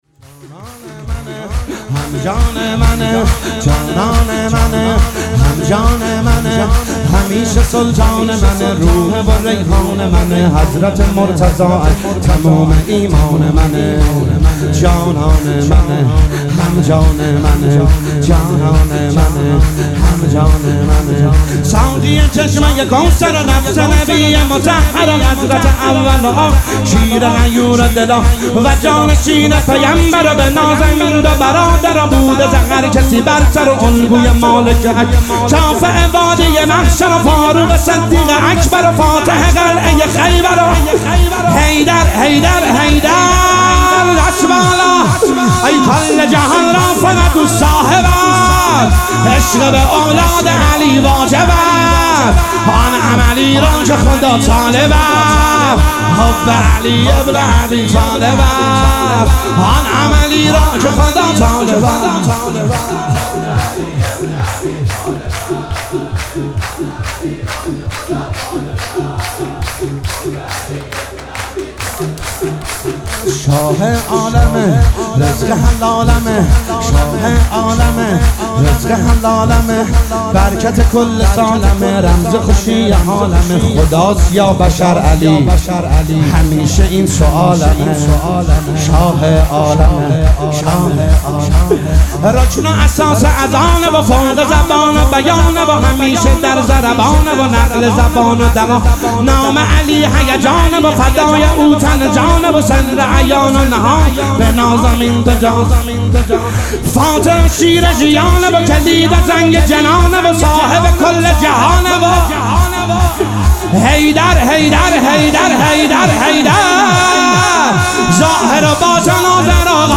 جان منه جانان منه - سرود